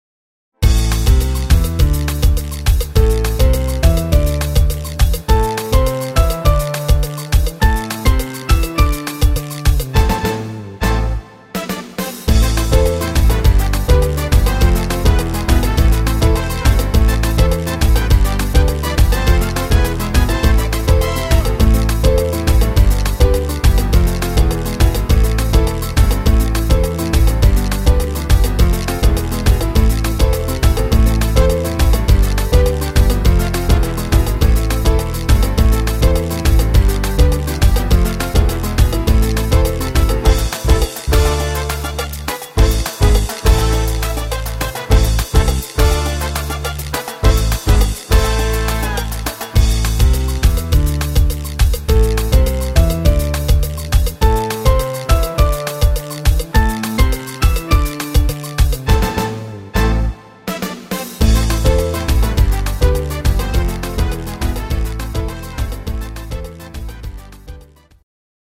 echter Salsa